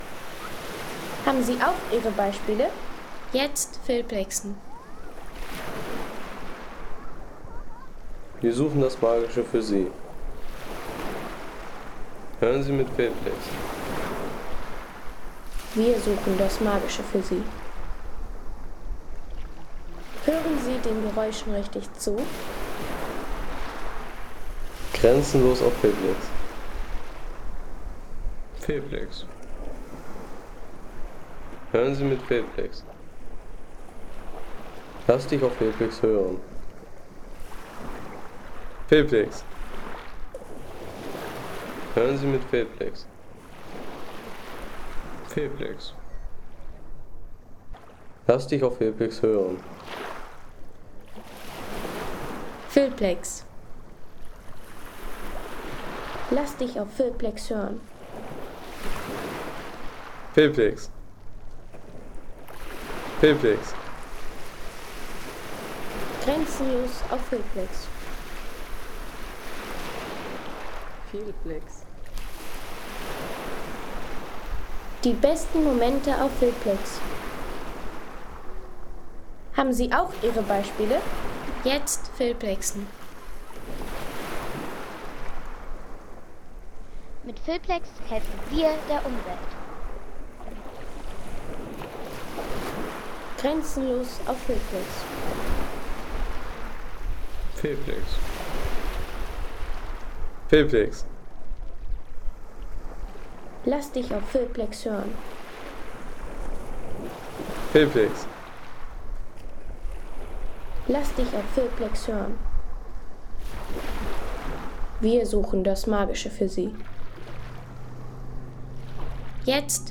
Lago Maggiore Strand Home Sounds Landschaft Strände Lago Maggiore Strand Seien Sie der Erste, der dieses Produkt bewertet Artikelnummer: 36 Kategorien: Landschaft - Strände Lago Maggiore Strand Lade Sound.... Pure Entspannung am öffentlichen Strand des Lago Maggiore in Verbania ... 3,50 € Inkl. 19% MwSt.